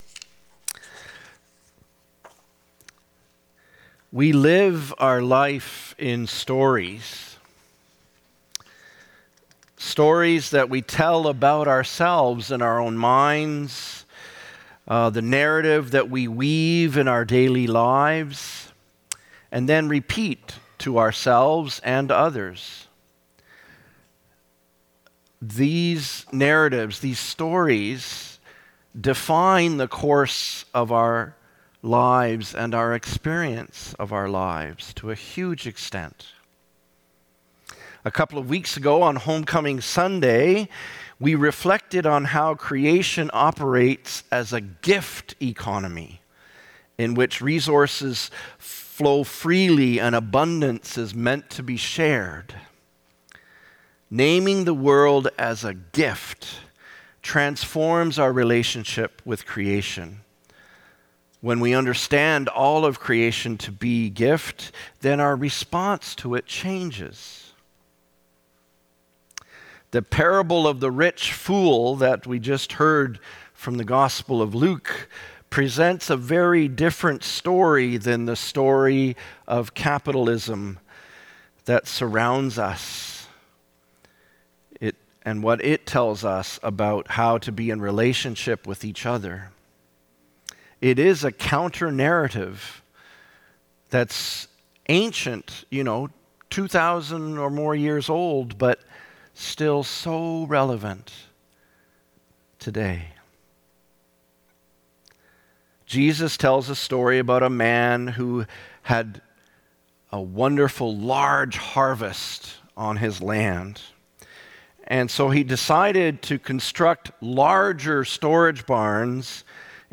Sermons | Gilmore Park United Church
September 21 2025 - Reflection